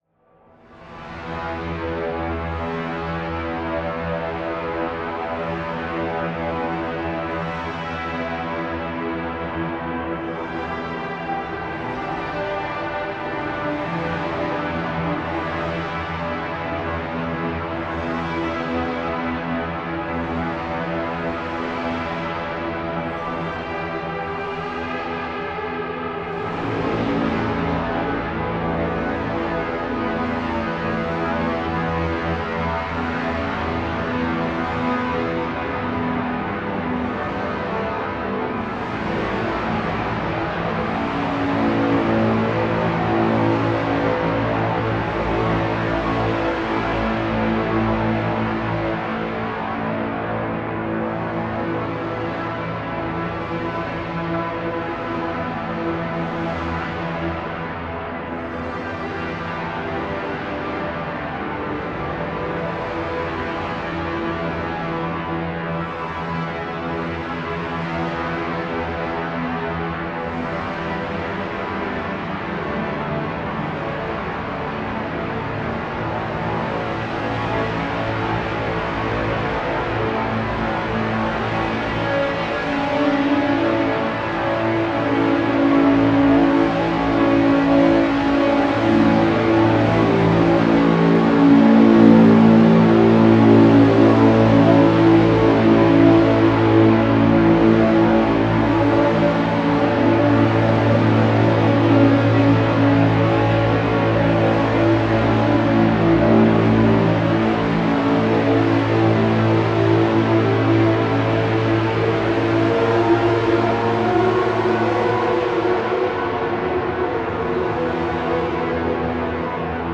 the halls of valhalla cartridge features 8 original reverberation algorithms, designed to work with the strengths of the z-dsp, and tailored towards electronic music.
the next example is guitar, being fed through a b:assmaster fuzz, straight into the z-dsp, running the ginnungagap algorithm. the mix is set to 100% wet, and the decay is turned up somewhat.